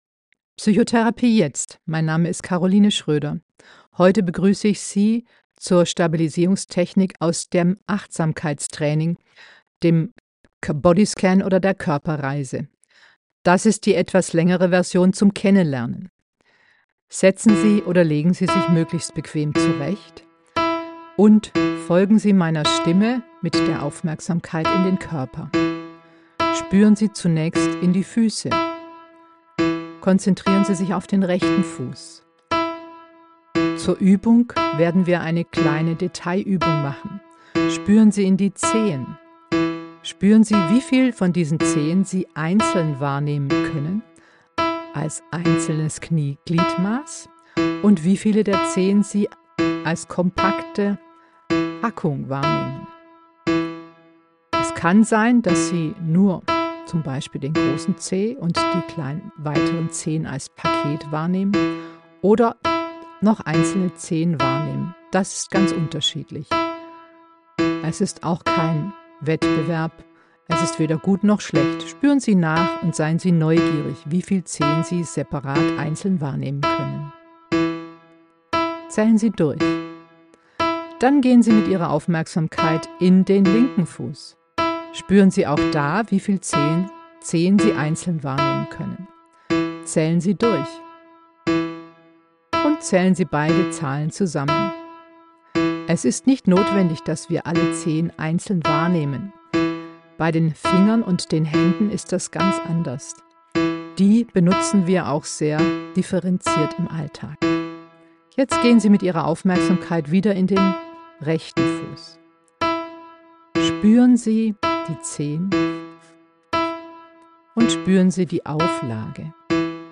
Wenn Sie sich fragen, ob die akustischen Signale (Klavier oder Bongo) nicht ganz im Rhythmus sind, da haben Sie recht. Mit diesem nicht ganz regelmäßigen Tönen wird das Gehirn etwas mehr gefordert.